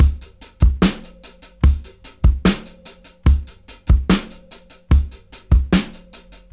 73-DRY-02.wav